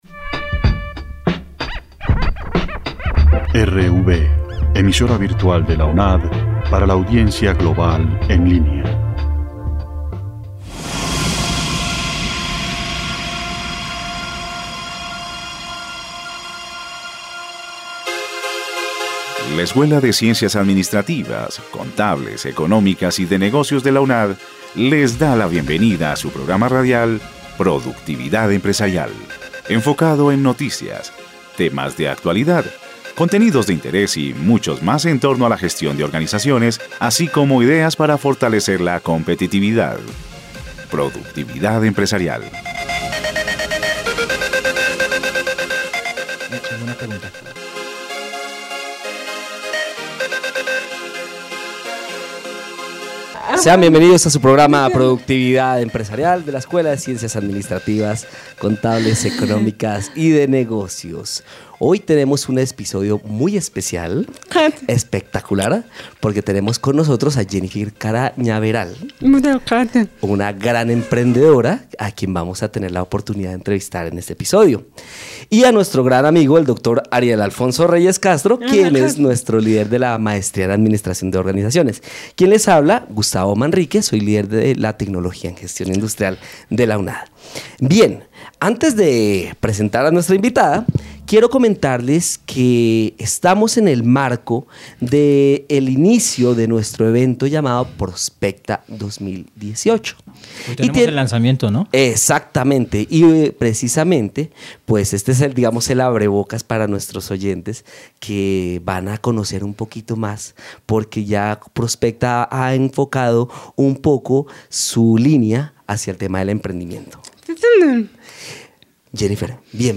Programa radial